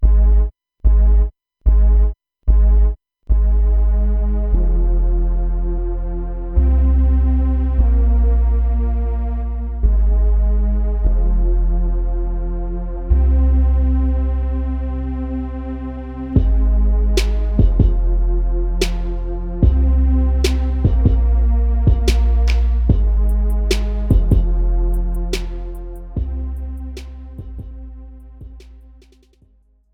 Without Backing Vocals. Professional Karaoke Backing Tracks.
Indie